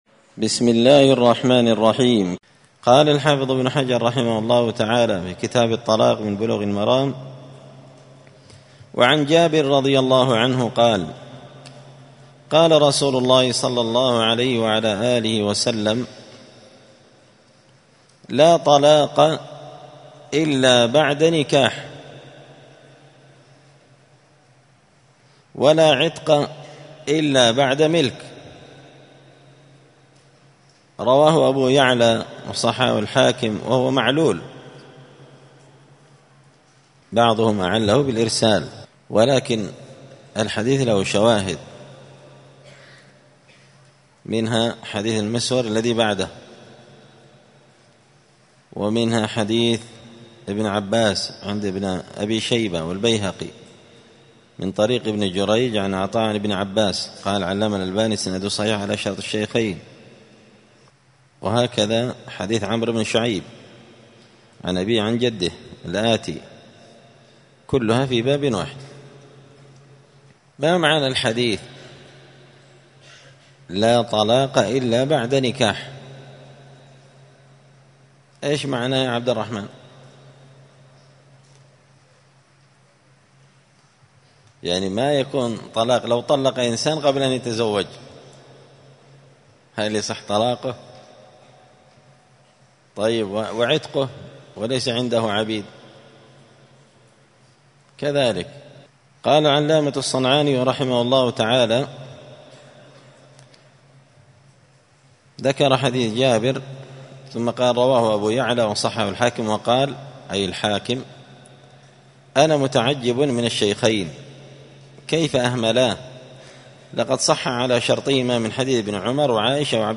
*الدرس السابع (7) {حكم طلاق الأجنبية}*